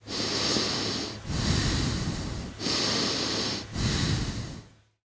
瞎子房间癞子呼吸.ogg